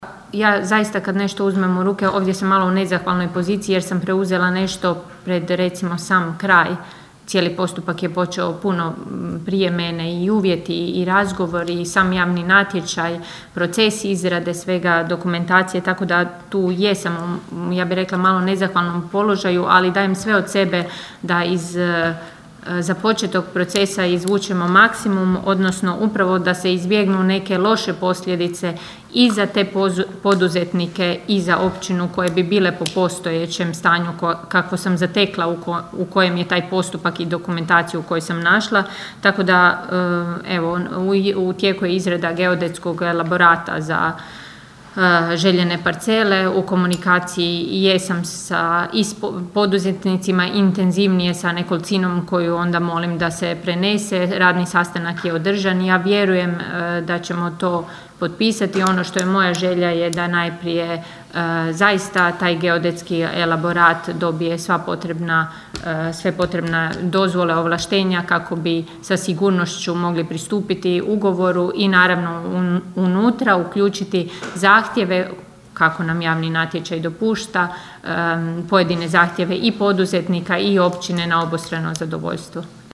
Na prošlotjednoj sjednici Općinskog vijeća Kršana dva su zanimljiva pitanja uputili vijećnik s Nezavisne liste Romana Carića Boris Rogić i nezavisni vijećnik Valdi Runko.
ton – Ana Vuksan 1), zaključila je kršanska općinska načelnica Ana Vuksan.